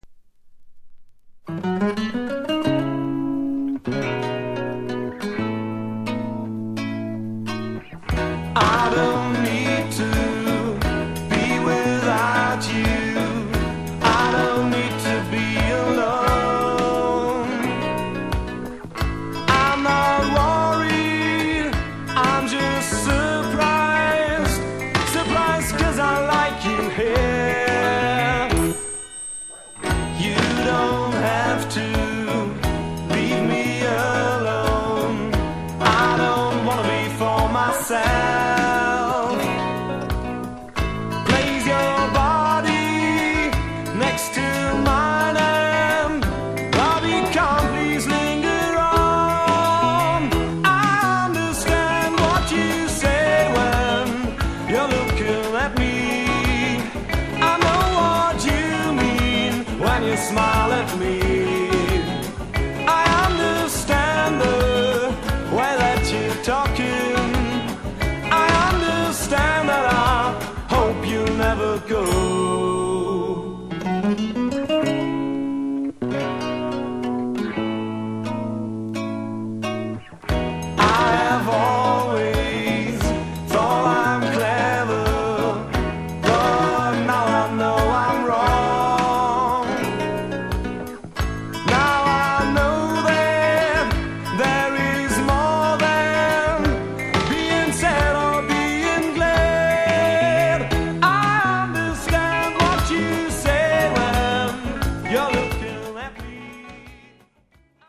80'S - 90'S RARE NEO ACOUSTIC LISTS  (A-D)